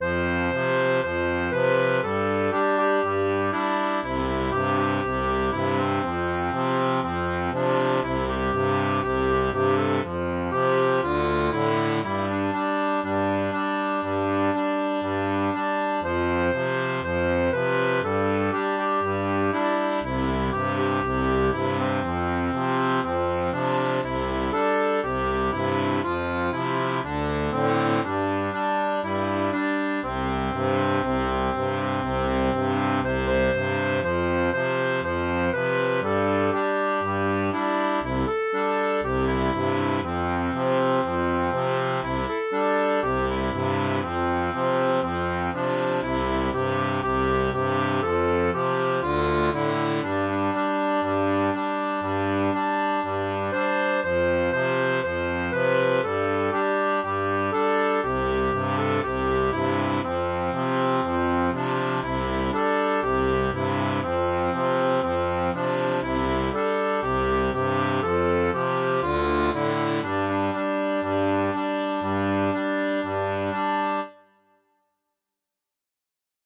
Type d'accordéon
Folk et Traditionnel